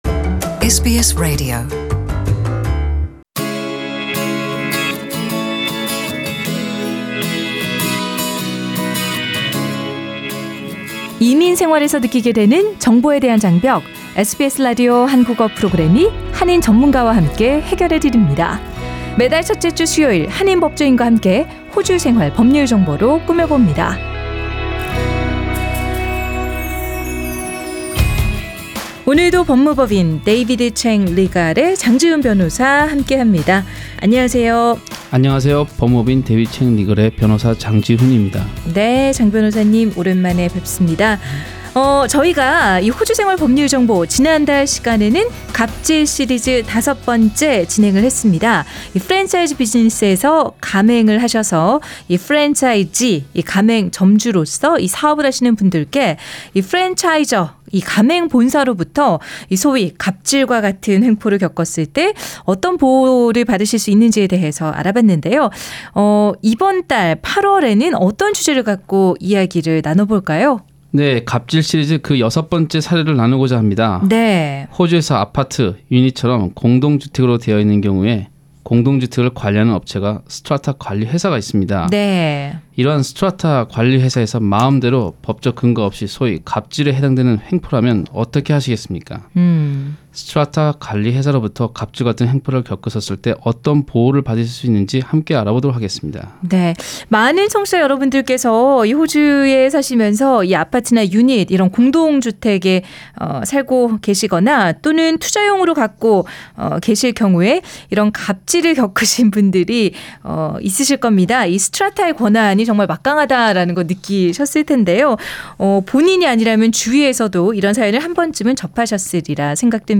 [상단의 팟캐스트를 통해 전체 인터뷰를 들으실 수 있습니다] Share